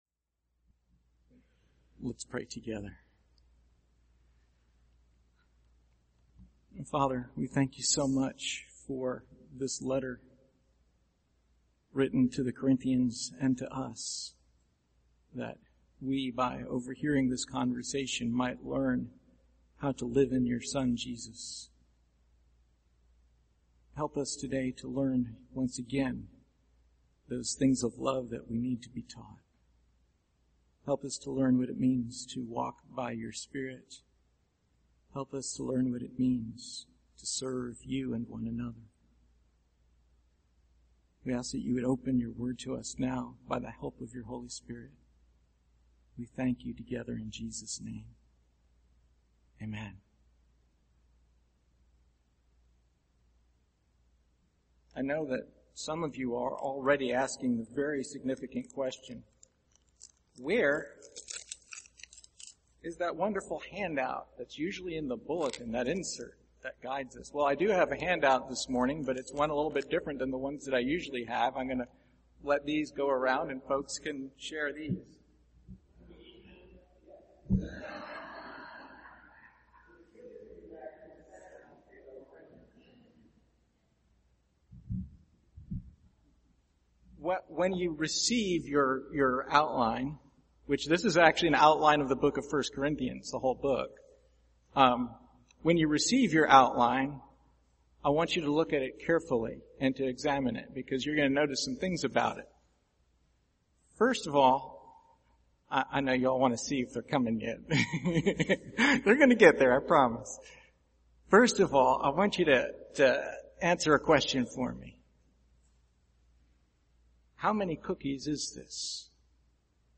Passage: 1 Corinthians 16:14-24 Service Type: Sunday Morning